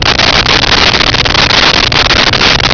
Sfx Amb Pumpfact Loop
sfx_amb_pumpfact_loop.wav